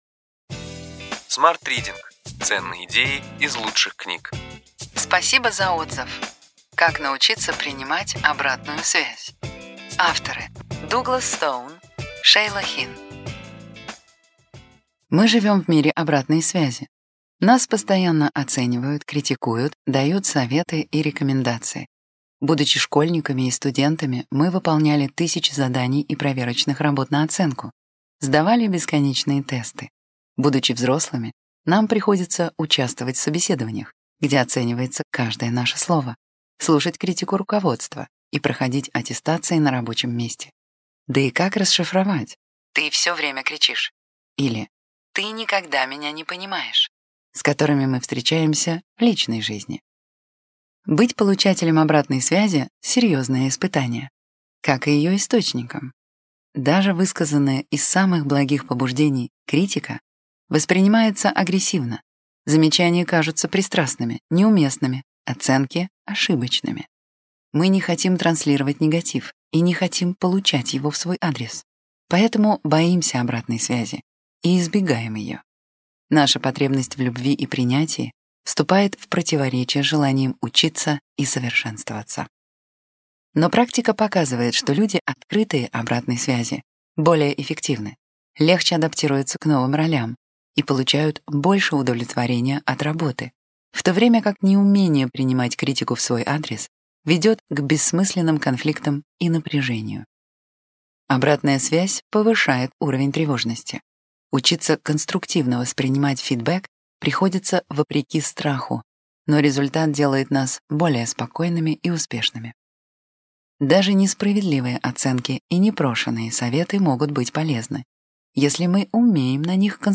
Аудиокнига Ключевые идеи книги: Спасибо за отзыв. Как научиться принимать обратную связь.